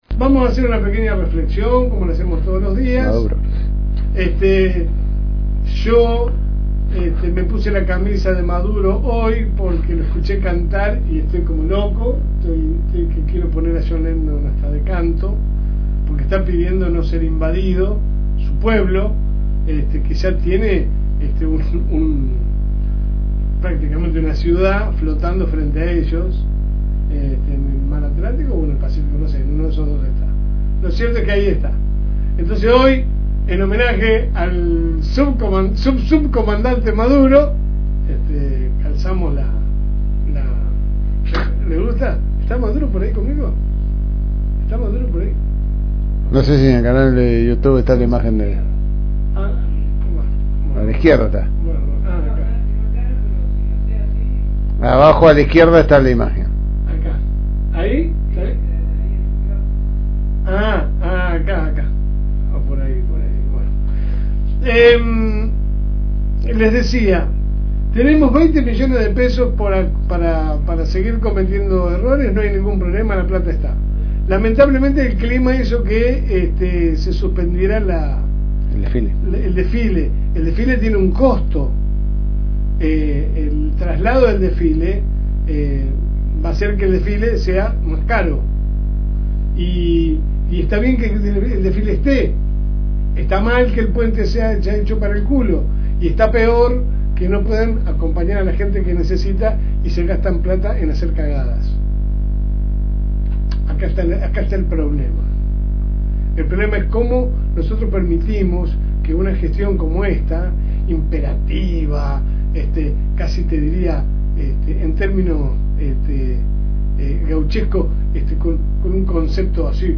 Su programa sale de lunes a viernes de 10 a 12 HS por el aire de la FM Reencuentro 102.9